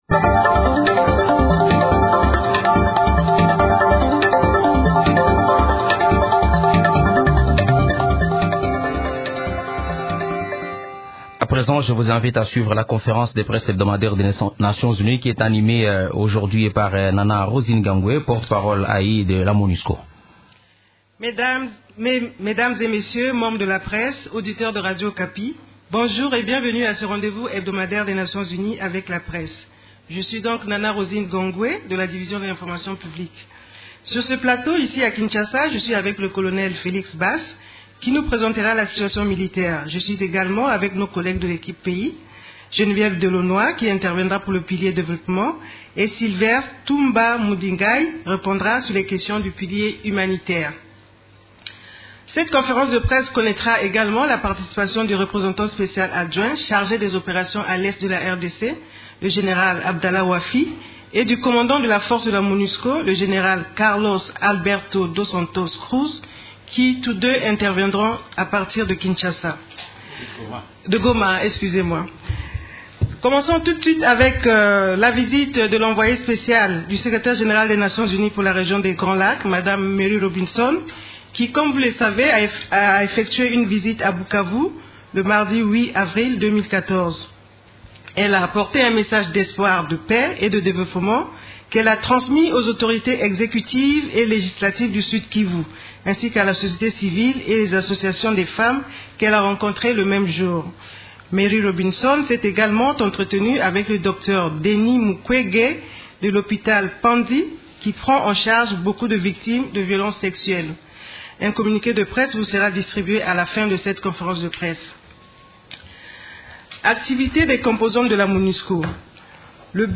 La conférence hebdomadaire des Nations unies du mercredi 9 avril à Kinshasa a abordé les sujets suivants:
Conf-de-presse1.mp3